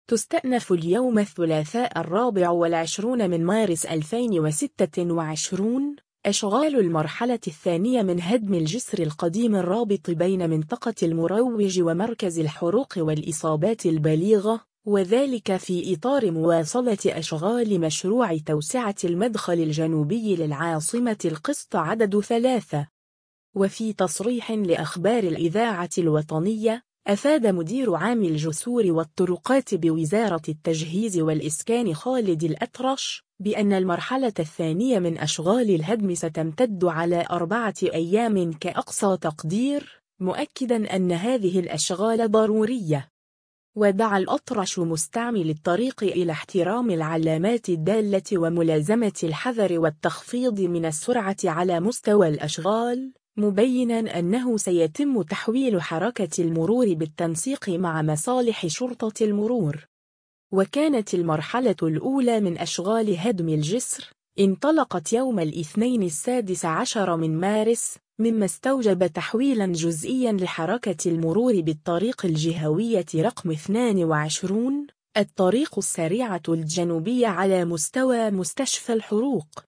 وفي تصريح لأخبار الإذاعة الوطنية، أفاد مدير عام الجسور والطرقات بوزارة التجهيز والإسكان خالد الأطرش، بأن المرحلة الثانية من أشغال الهدم ستمتد على 4 أيام كأقصى تقدير، مؤكدا أن هذه الأشغال ضرورية.